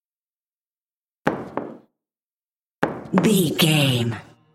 Billards ball hit corner
Sound Effects
hard